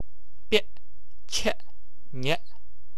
听感颇似普通话（ie）的短音。